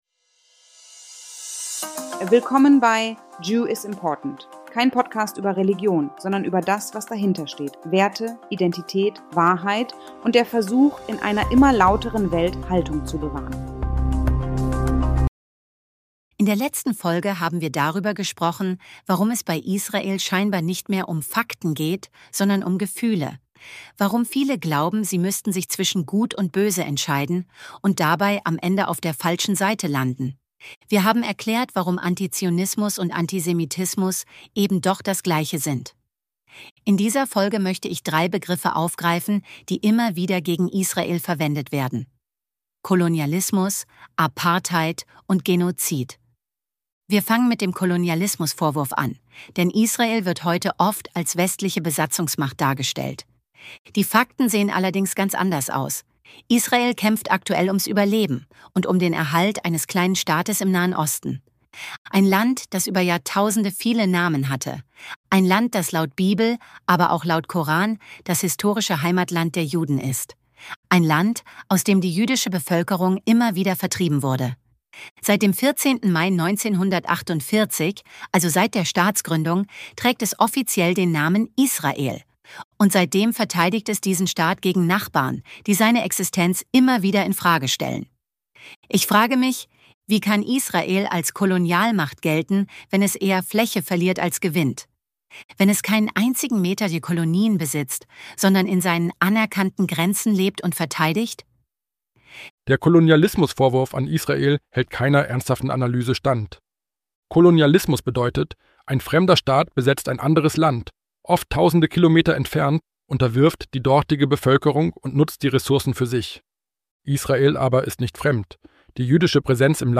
© 2025 AI-generated content.